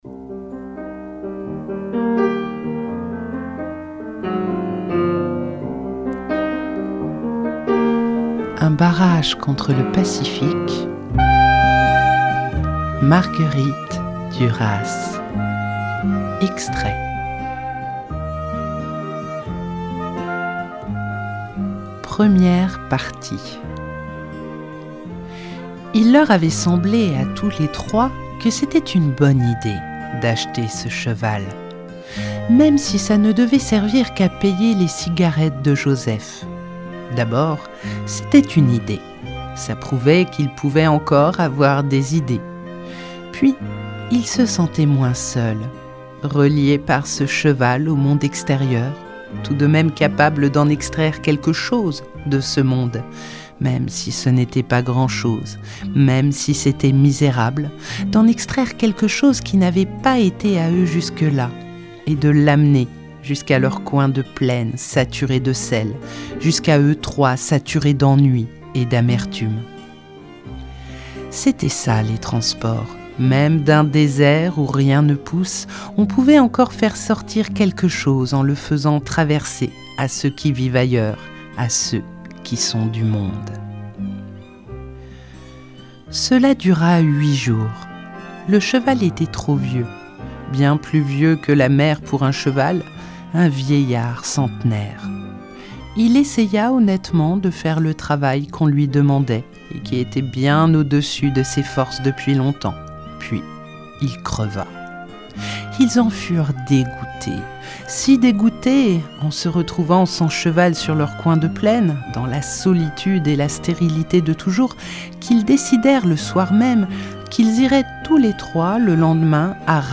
Extraits (25:21)